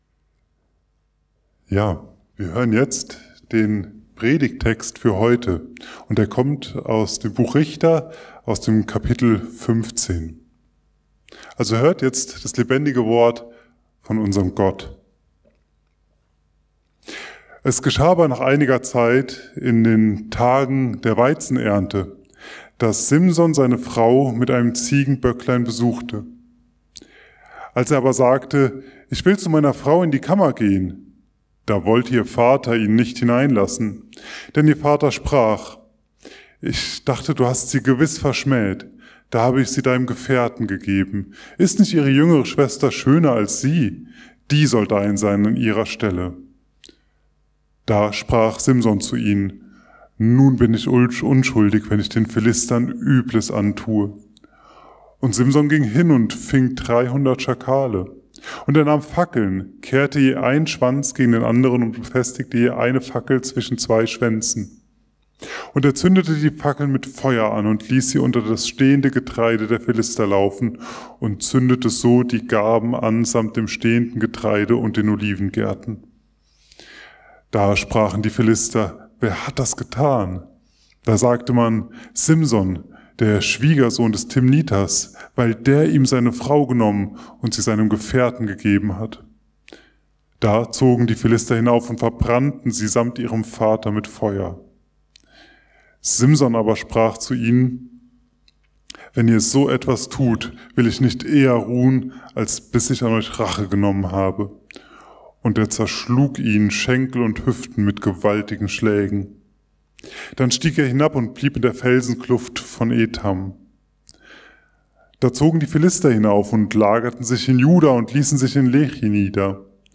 Aus Wessen Kraft Handeln Wir? ~ Mittwochsgottesdienst Podcast